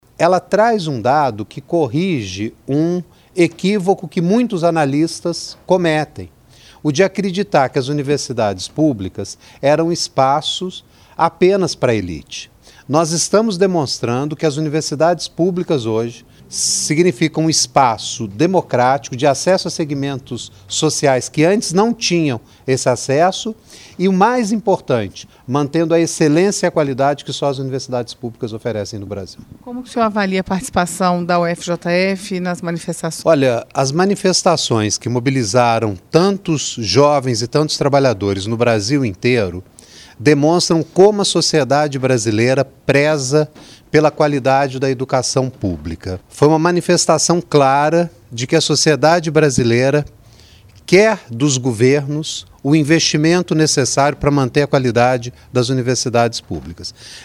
O reitor da UFJF, Marcus David, analisou o cenário da universidade pública a partir dos dados apresentados.